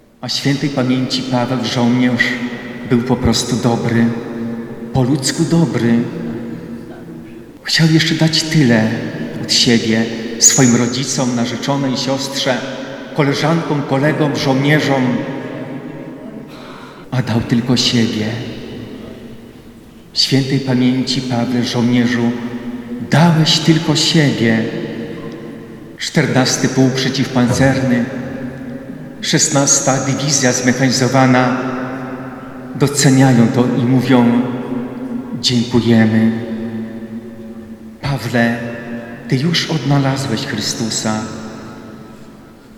Pogrzeb żołnierza 14. Pułku Przeciwpancernego w Suwałkach odbył się w piątek (04.07) w kościele parafialnym pod wezwaniem Matki Bożej Miłosierdzia.
Kazanie-2.mp3